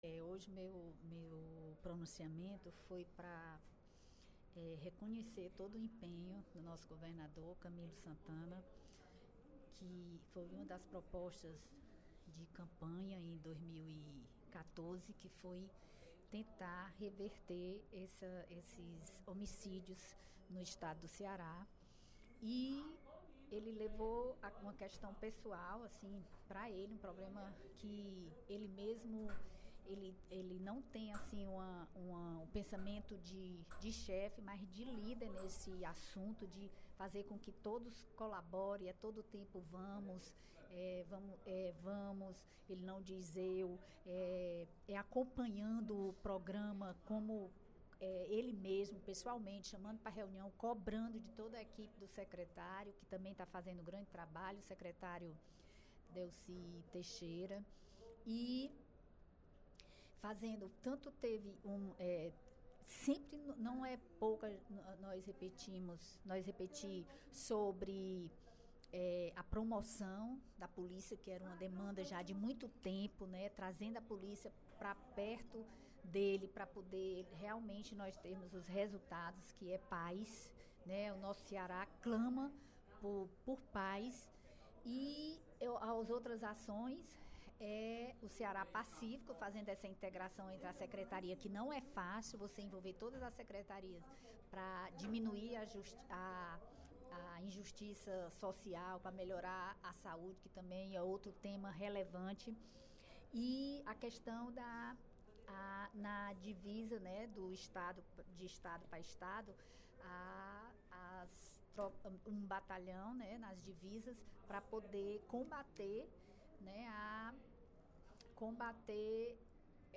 A deputada Bethrose (PRP) comentou a queda de 10% no índice de homicídios no Ceará durante o primeiro expediente da sessão plenária desta quarta-feira (03/06).